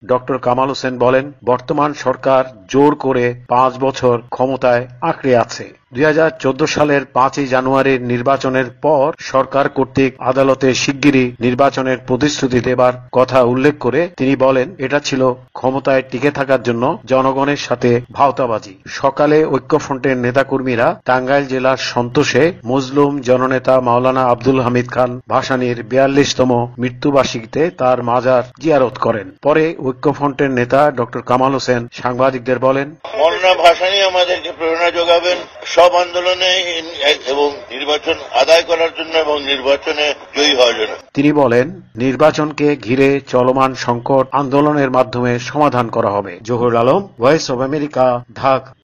শনিবার বিকেলে ঢাকায় জাতীয় আইনজীবী ঐক্য ফ্রন্ট আয়োজিত আইনজীবীদের সমাবেশে এমন আহ্বান জানিয়ে ড. কামাল হোসেন বলেন বর্তমান সরকার জোর করে ৫ বছর ক্ষমতা আঁকড়ে আছে।
রিপোর্ট